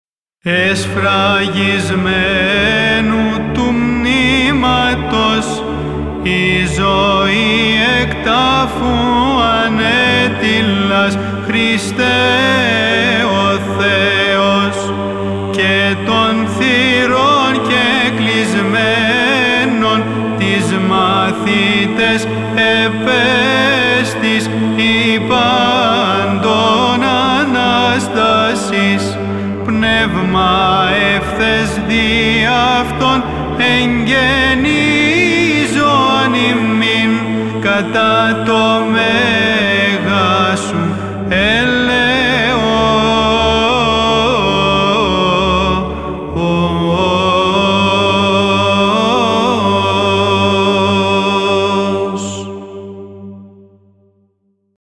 Ἦχος βαρὺς.